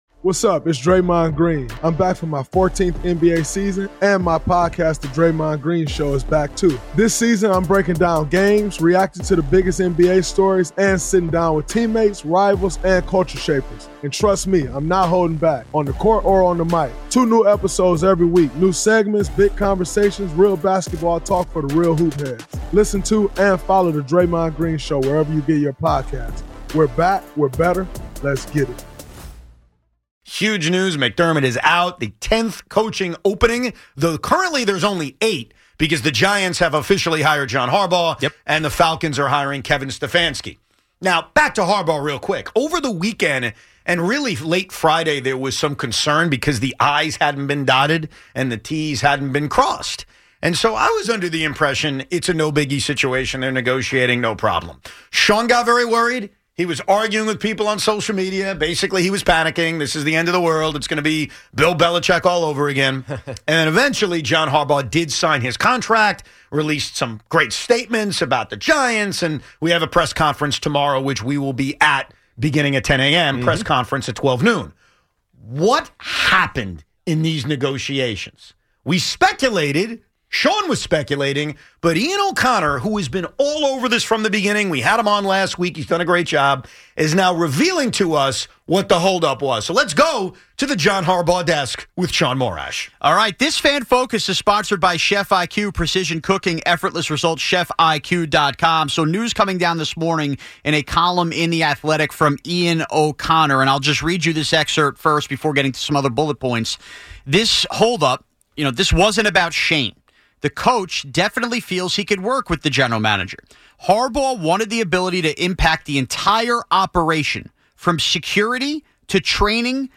The hour rolls on with calls and a look at what comes next with coordinators and the remaining coaching openings.